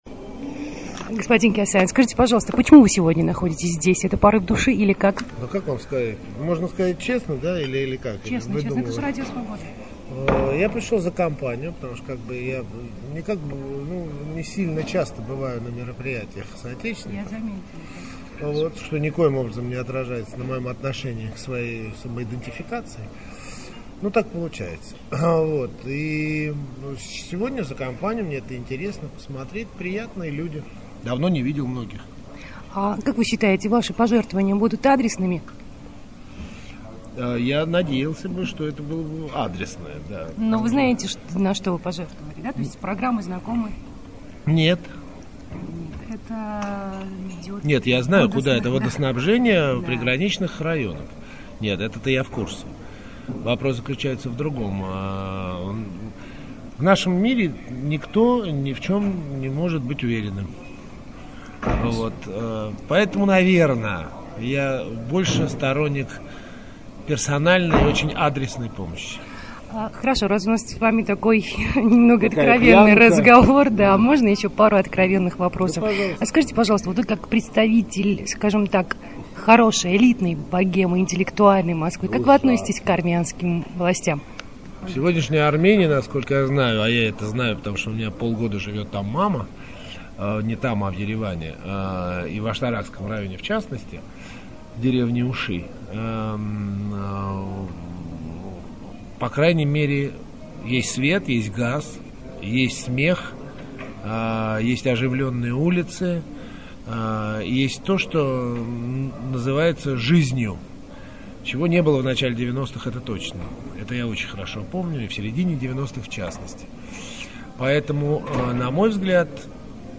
Эксклюзивное интервью Радио Азатутюн с Тиграном Кеосаяном